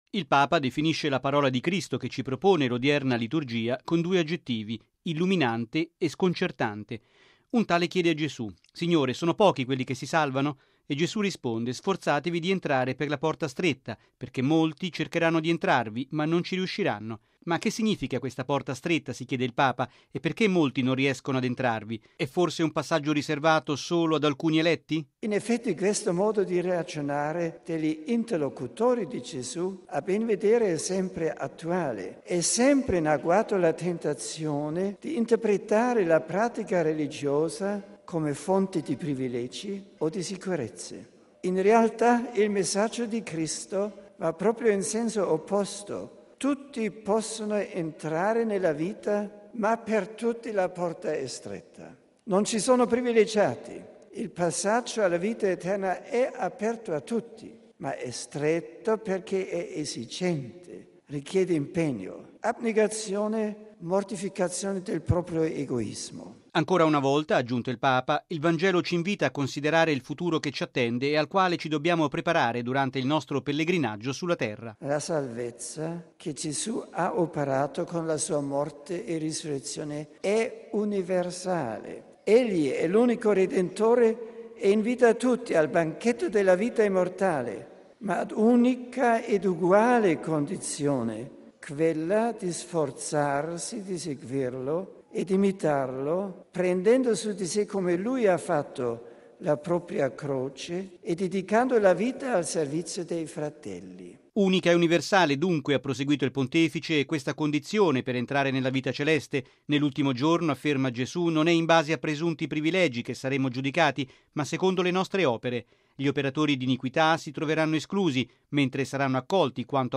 E’ quanto ha affermato oggi il Papa durante l’Angelus a Castel Gandolfo.